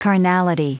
Prononciation du mot carnality en anglais (fichier audio)
Prononciation du mot : carnality